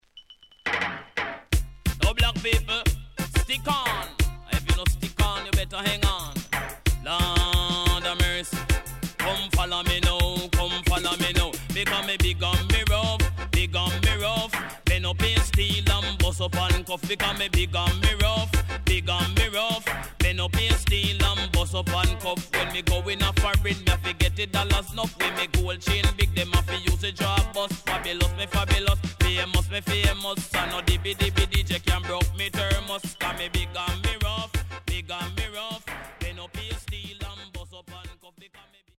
HOME > LP [DANCEHALL]
SIDE B:少しチリノイズ入りますが良好です。